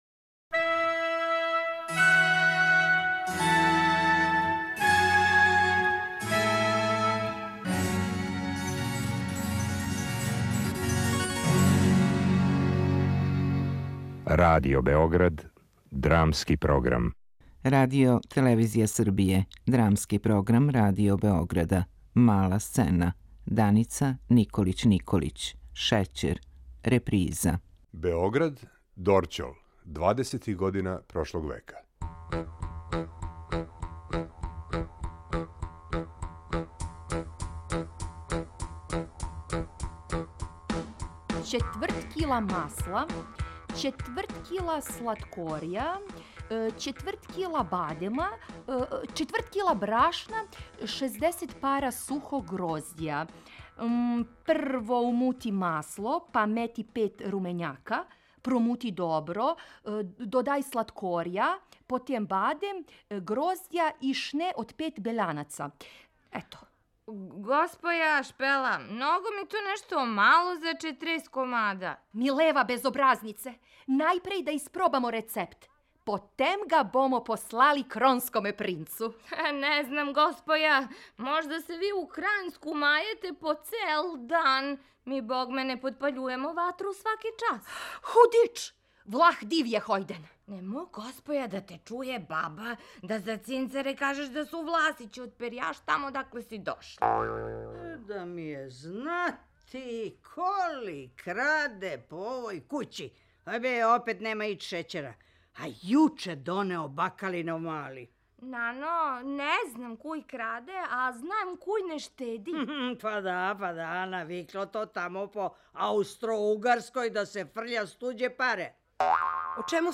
Драмски програм